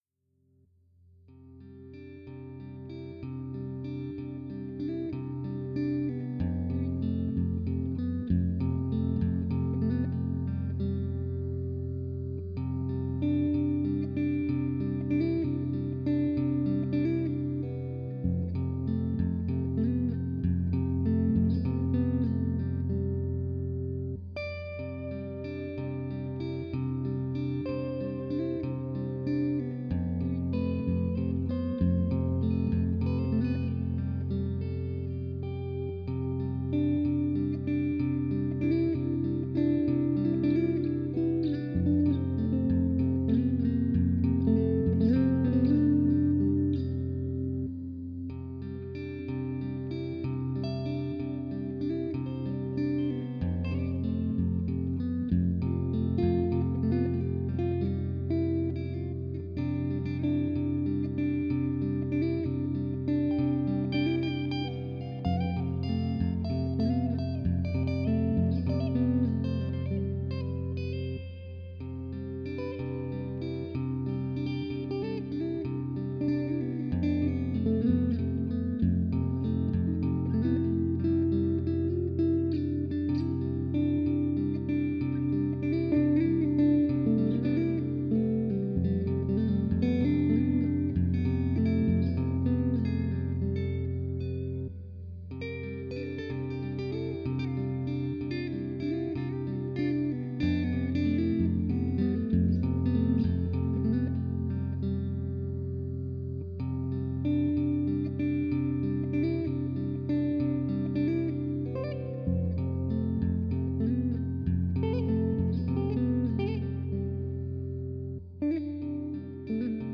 Peaceful guitar loop for Film/TV/Game etc